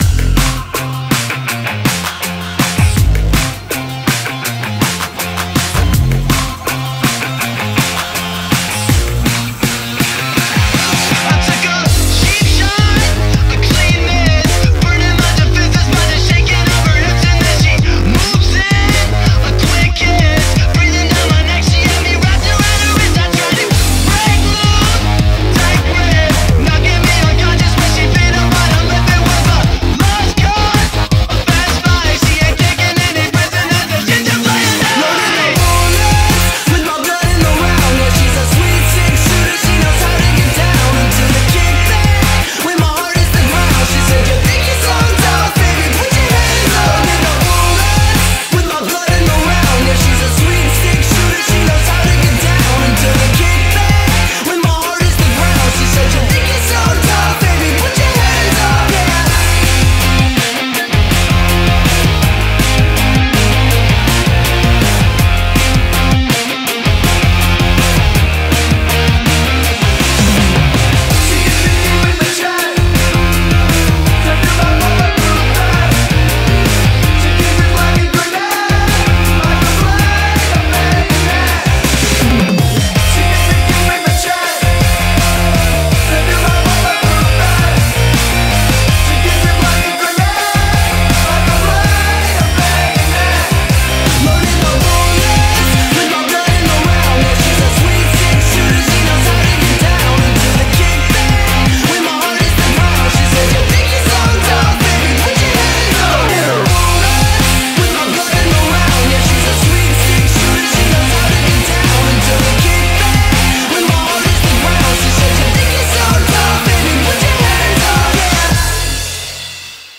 BPM81-162
Audio QualityPerfect (High Quality)
Comments[SPACE PUNK]